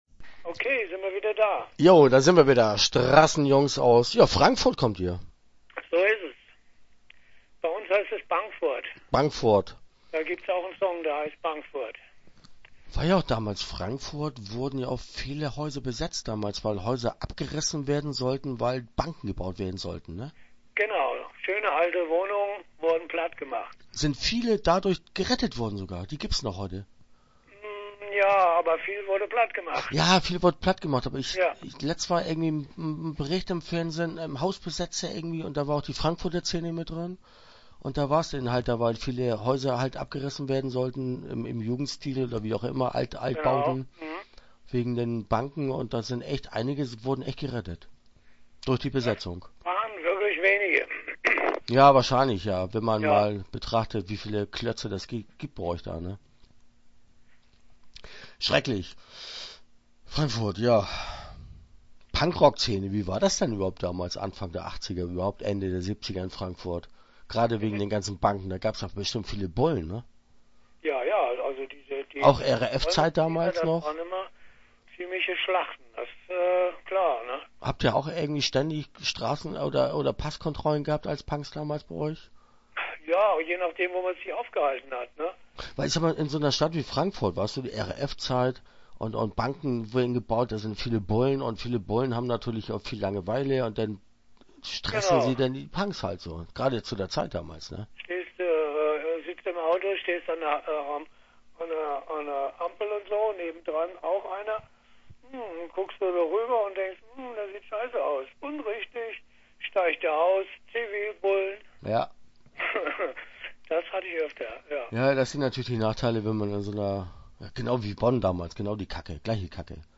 Interview Teil 1 (9:54)